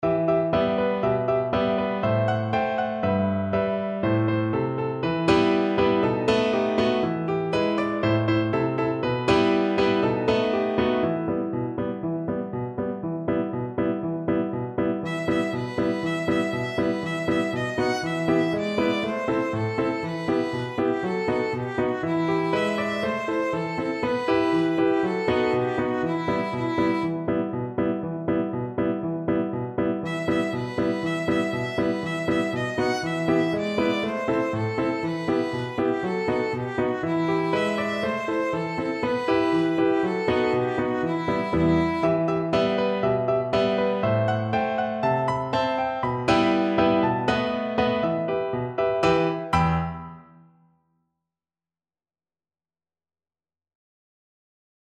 Violin version
Violin
Traditional Music of unknown author.
E minor (Sounding Pitch) (View more E minor Music for Violin )
Allegro (View more music marked Allegro)
2/4 (View more 2/4 Music)
E5-F#6
oi_marichko_VLN.mp3